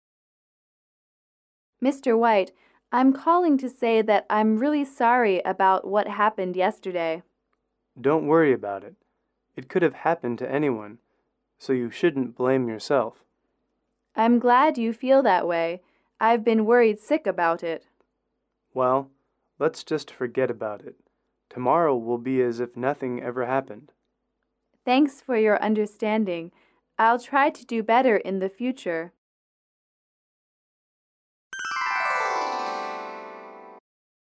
英语口语情景短对话28-3：道歉（MP3）